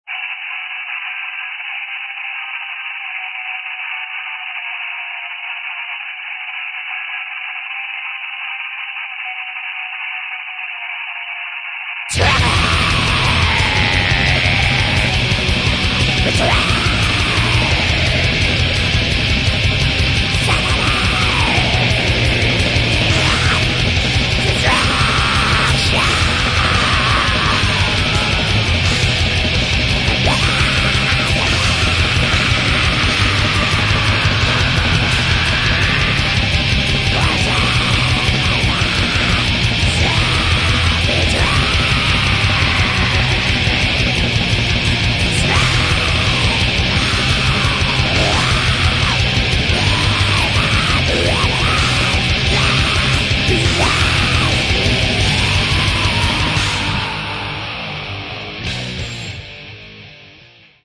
Metal
безумный и уверенно-святотатственный Black Metal.